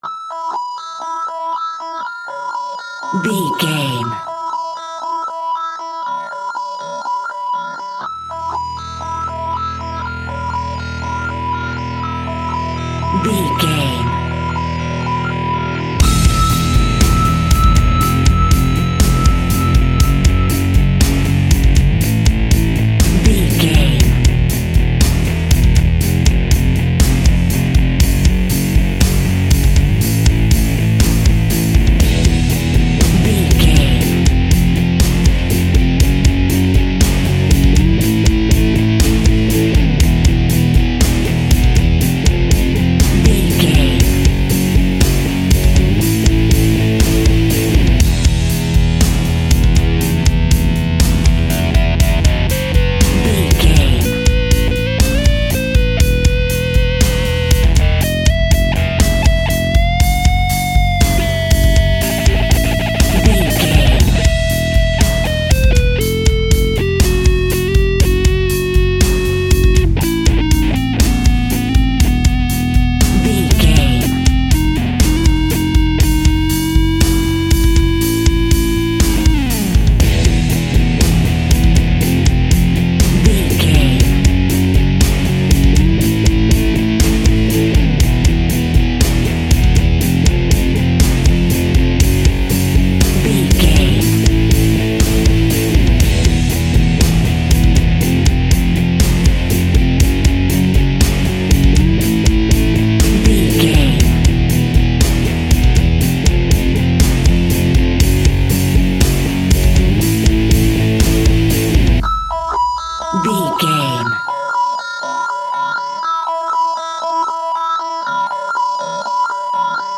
Epic / Action
Ionian/Major
hard rock
heavy metal
distortion
rock guitars
Rock Bass
heavy drums
distorted guitars
hammond organ